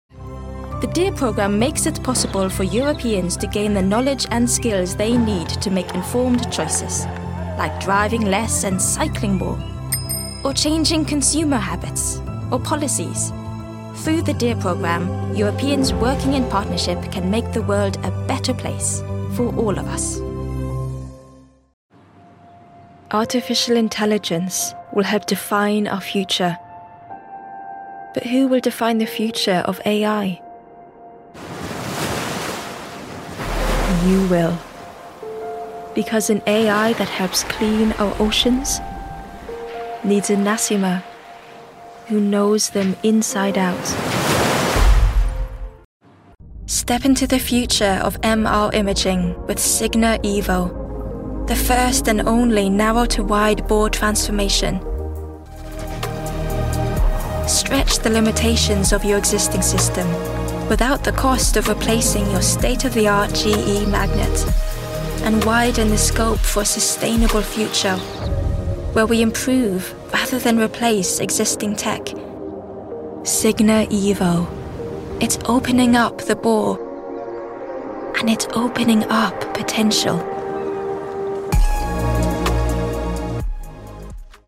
Corporate Reel - Accented English
I'm a highly experienced Scandinavian voice artist with a fully equipped home studio in London.